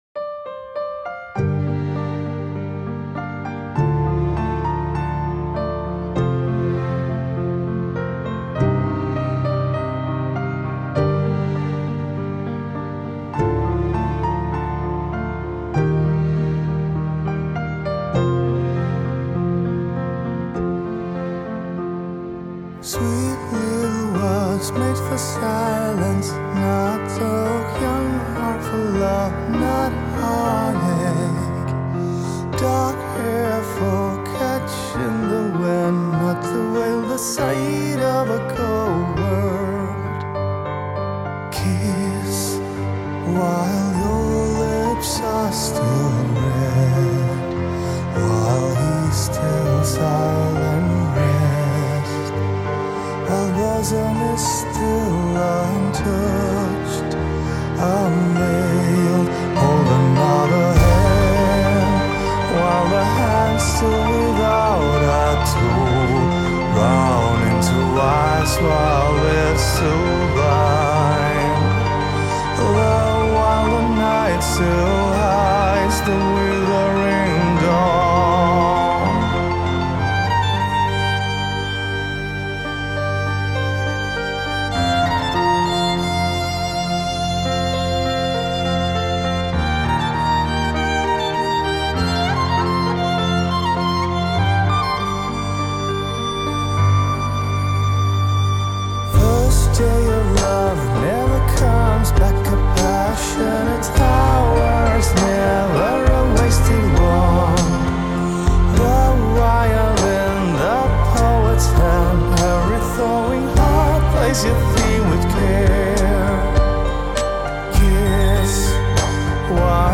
این هم یه اهنگ اروم برای کسایی که دوست دارن